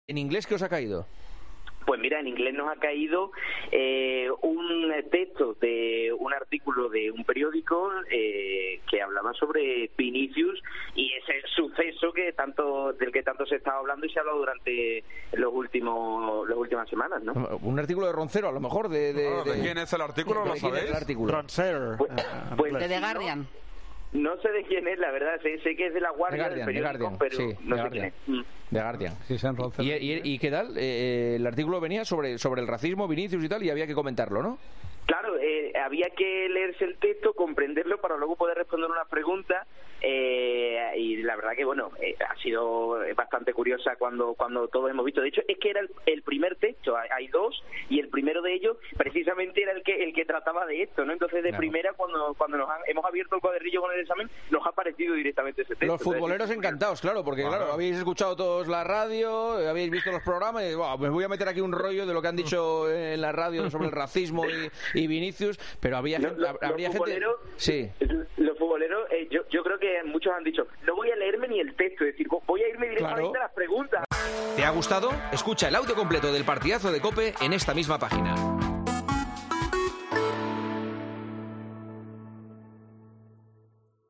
Un alumno explica cómo fue ese examen en 'El Partidazo de COPE'